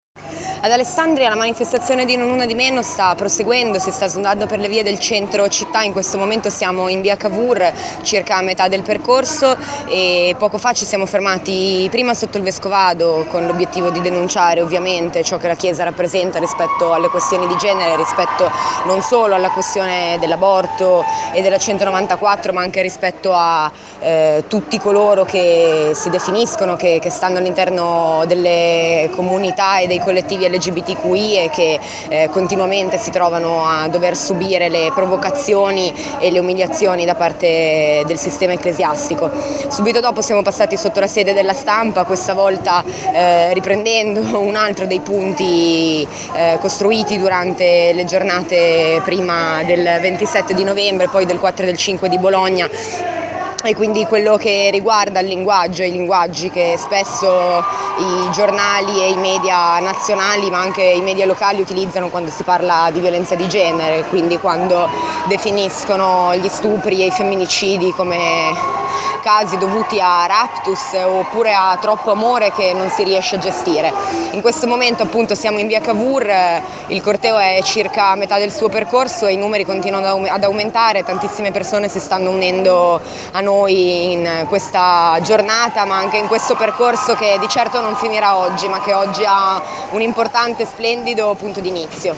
la promessa delle mille persone che hanno preso parte alla manifestazione (ascolta i contributi audio).
Una manifestazione colorata, rumorosa e determinata che ha attraversato le vie del centro toccando alcuni luoghi simbolo come le sedi del Vescovado e de La Stampa per rilanciare gli otto punti al centro della mobilitazione globale di Non una di Meno.